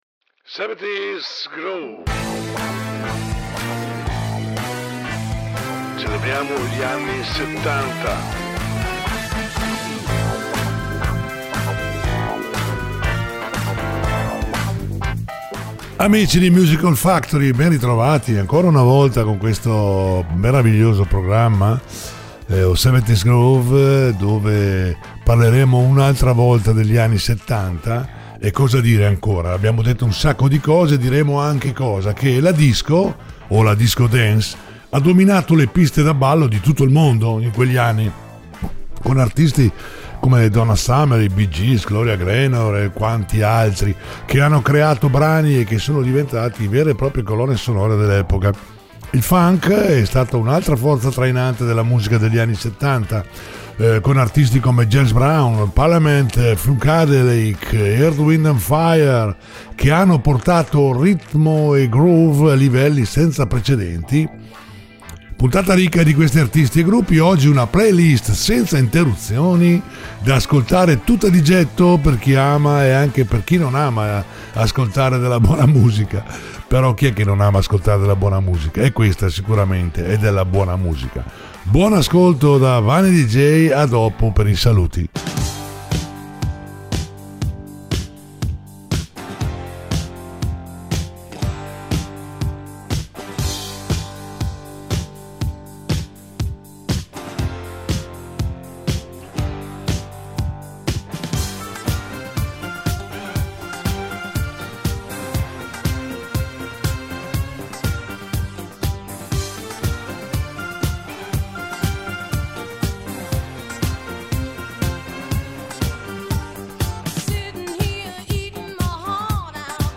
70's Groove